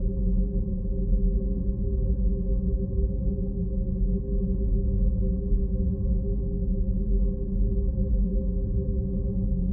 drone_loop.wav